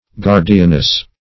Search Result for " guardianess" : The Collaborative International Dictionary of English v.0.48: Guardianess \Guard"i*an*ess\, n. A female guardian.
guardianess.mp3